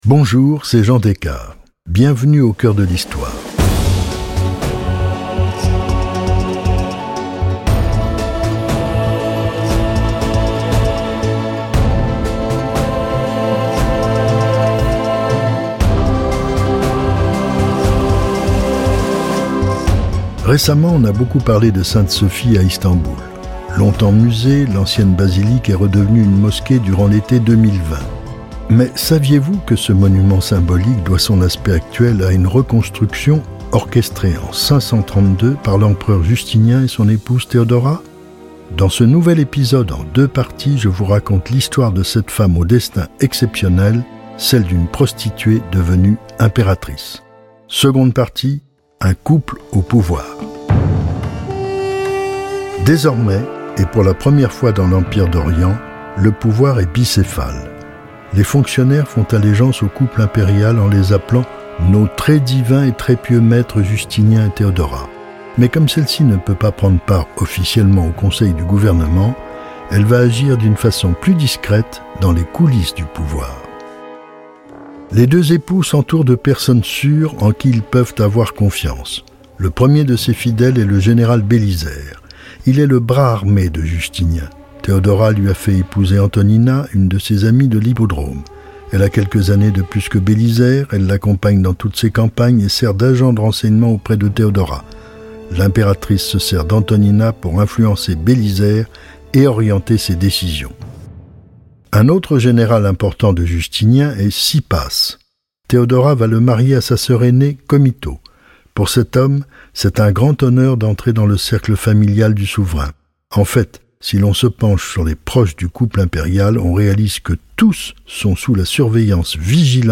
Après l’échec de son premier mariage, Théodora est parvenue à se hisser de la courtisanerie au trône de Byzance. Dans ce nouvel épisode du podcast Europe 1 Studio « Au cœur de l’histoire », Jean des Cars vous raconte le règne mémorable de cette impératrice pas comme les autres…